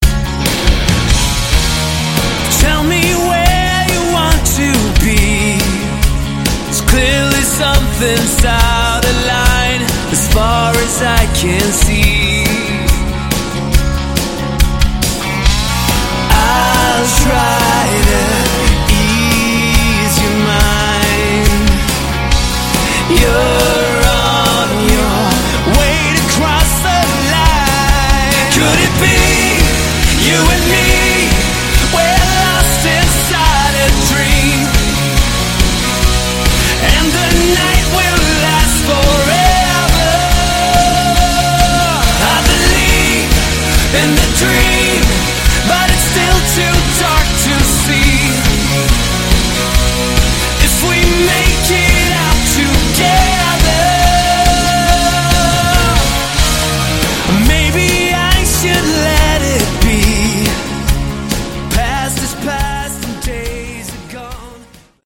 Category: Melodic Rock
vocals, guitar